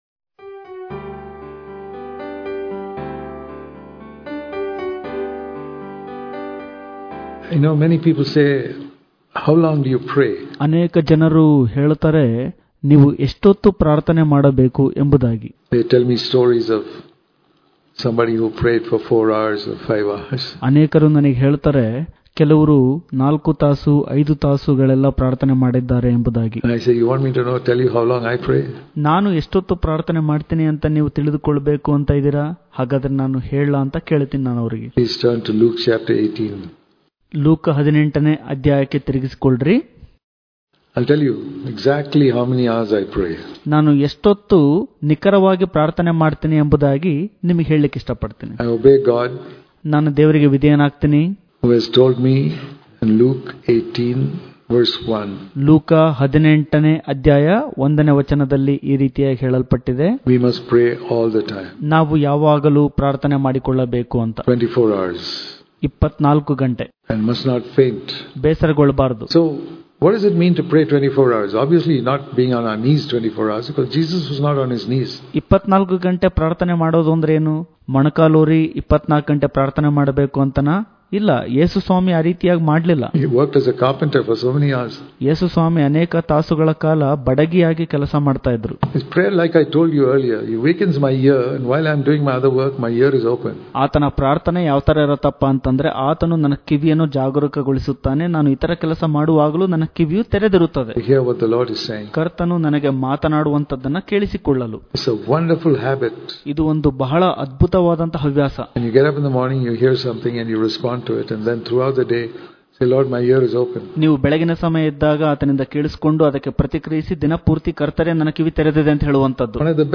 ಇಂದಿನ ಧ್ಯಾನ
Daily Devotions